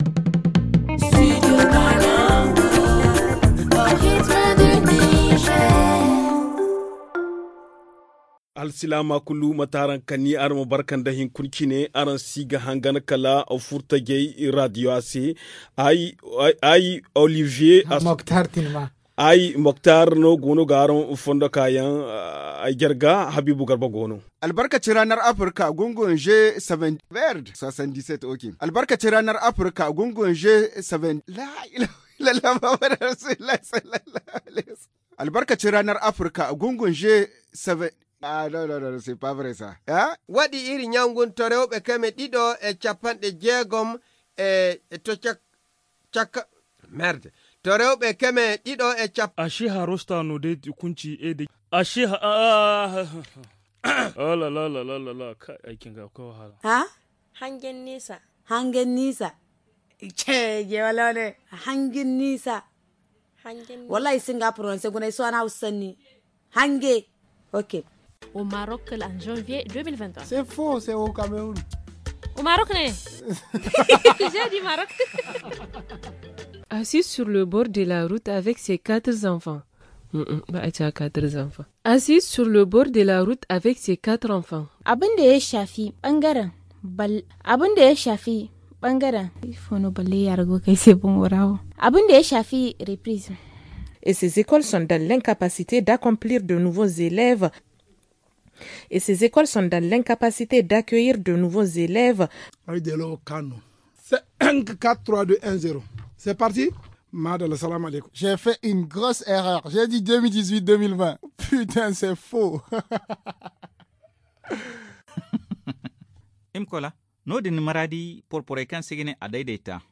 Bêtisiers des journalistes du studio Kalangou - Studio Kalangou - Au rythme du Niger
En cette dernière journée de 2021, le studio kalangou, consacre la partie magazine du journal aux bêtisiers de ses journalistes.